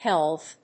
helve /hélv/
発音記号
• / hélv(米国英語)